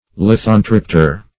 Lithontriptor \Lith"on*trip`tor\, n.